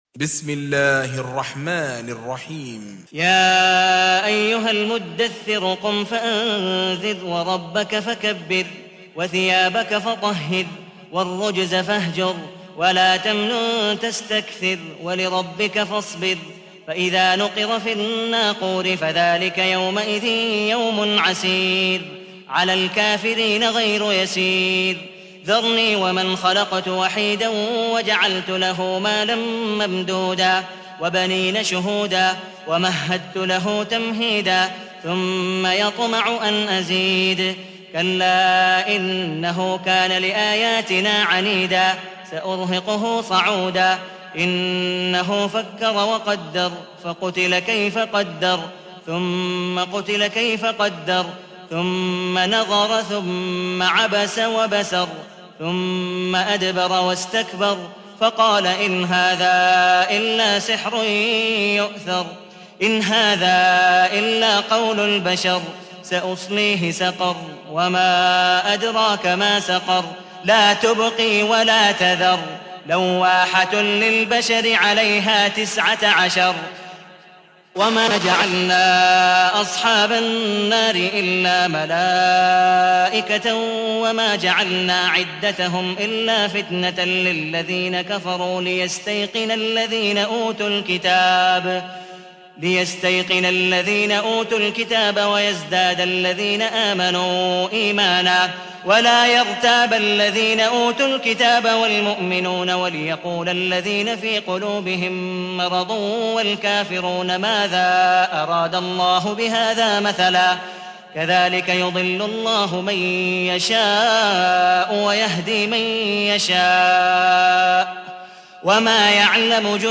تحميل : 74. سورة المدثر / القارئ عبد الودود مقبول حنيف / القرآن الكريم / موقع يا حسين